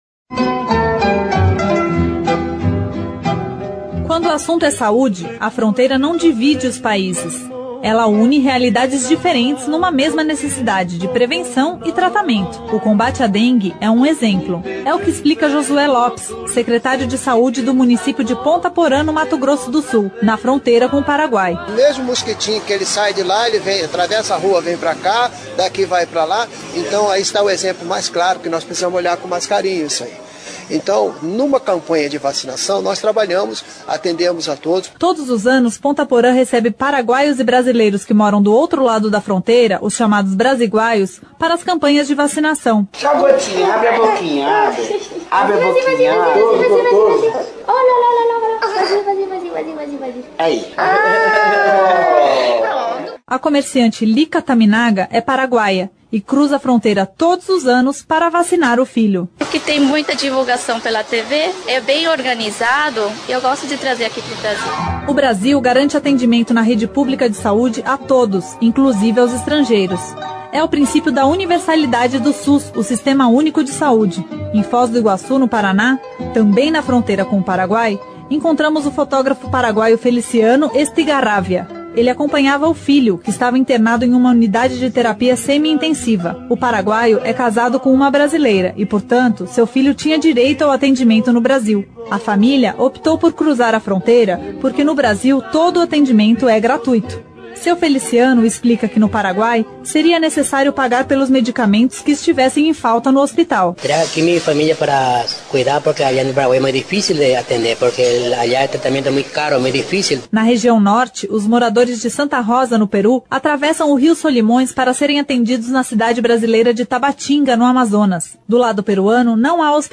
Reportagem especial Cidadania nas Fronteiras relata como é o atendimento à saúde de quem vive nas divisas do país